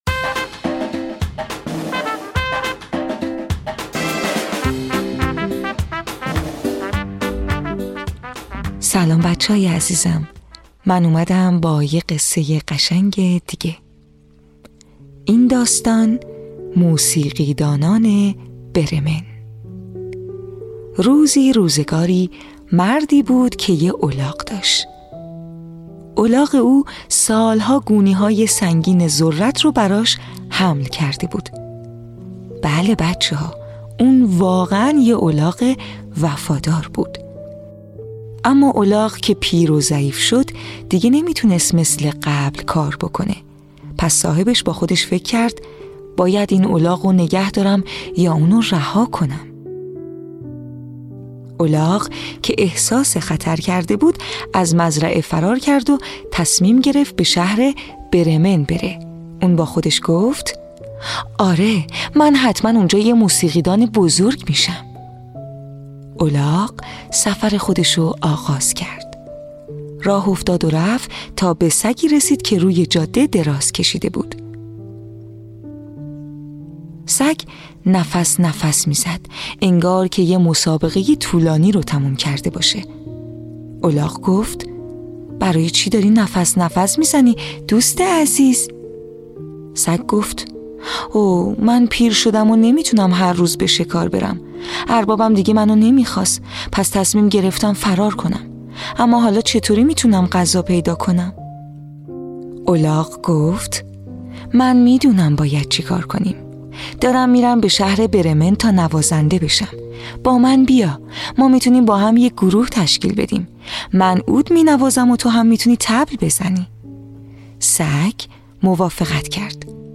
قصه های کودکانه صوتی – این داستان: موسیقیدانان برمن
تهیه شده در استودیو نت به نت